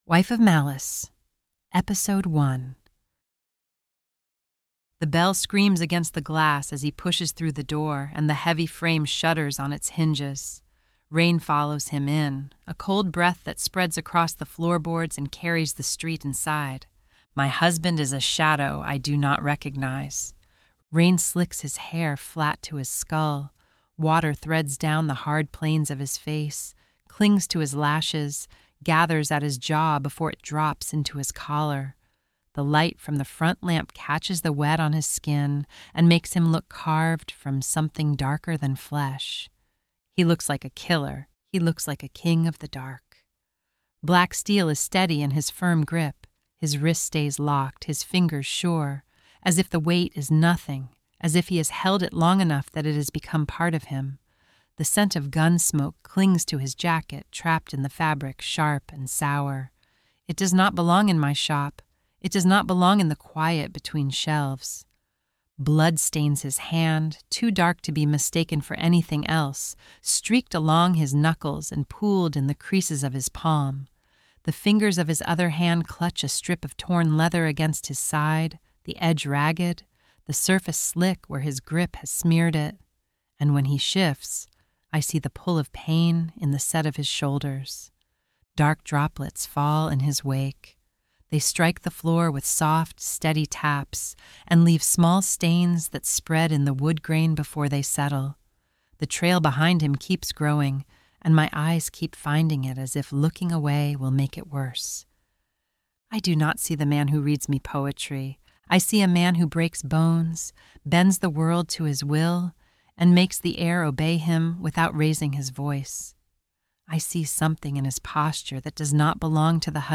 Wife of Malice is an audio romance with a paranormal twist, the first in a series I started called Villain Gets The Girl.